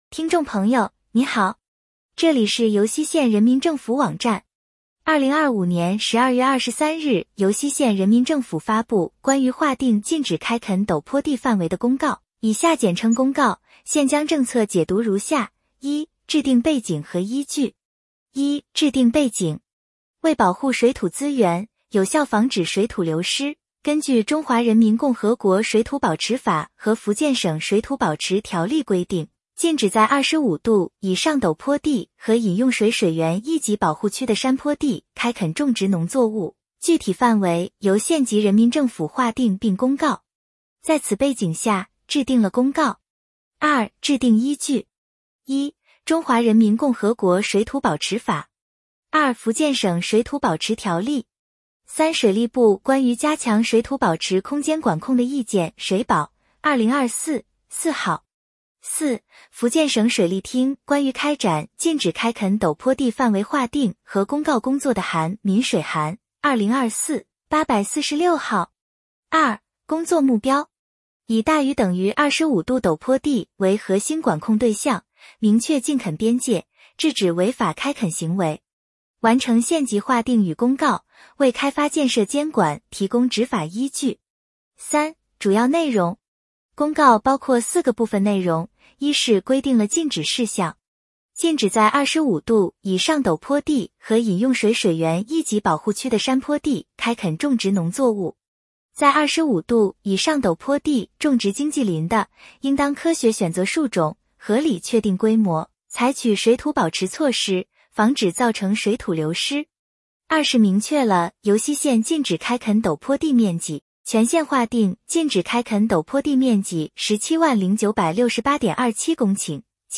音频解读：《尤溪县人民政府关于划定禁止开垦陡坡地范围的公告》